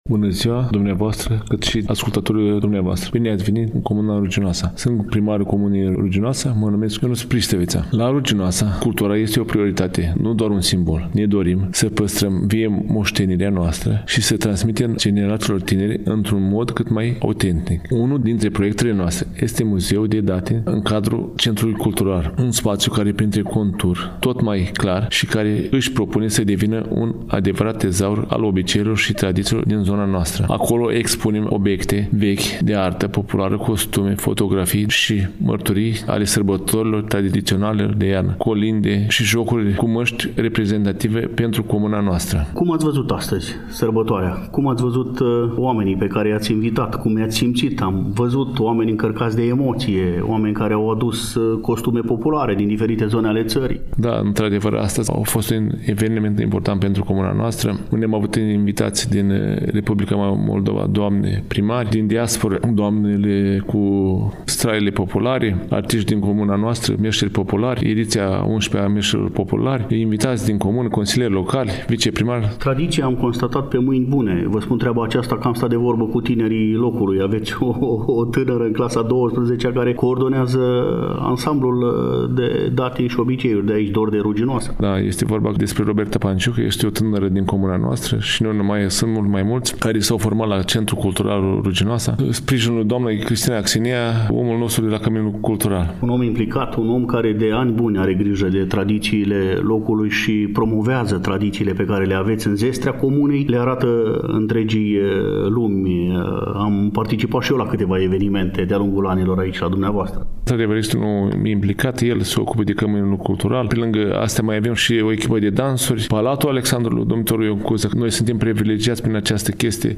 În următoarele minute, pentru că în ediția de astăzi a emisiunii Tradiții, facem popas în Ruginoasa, invităm la dialog pe edilul șef al comunei, Ionuț Constantin Pristăvița, cel care, succint, ne creionează, prin cuvânt, o imagine a actului cultural din localitate, cu accent pe evenimentele de zilele trecute din comună: Expoziția „Veșmintele Satului Românesc” și Târgul Meșterilor Populari, manifestare devenită deja tradiție, aflată la ediția a XI-a; edilul șef, pentru fiecare dintre dumneavoastră are o invitație ca în momentul când ajungeți în Ruginoasa, neapărat să faceți popas, pentru că aveți ce vizita.